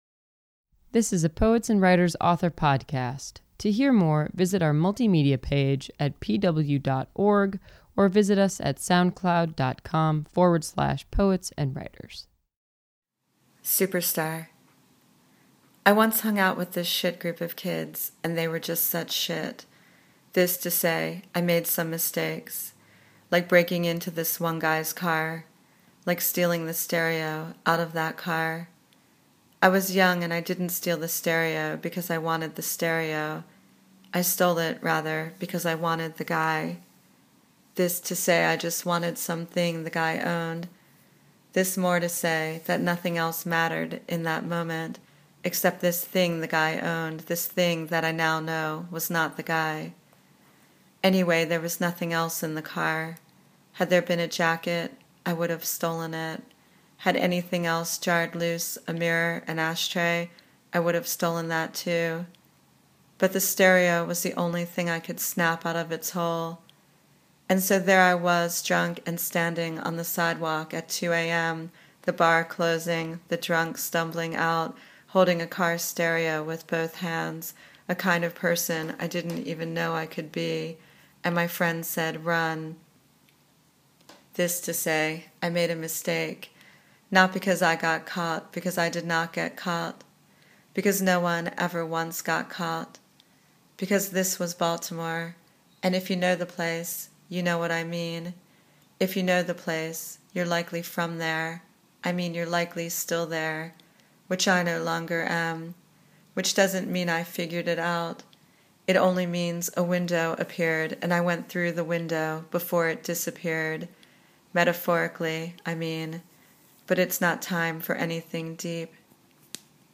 audio | fiction | short stories